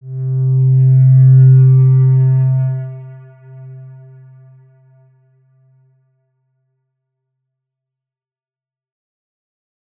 X_Windwistle-C2-mf.wav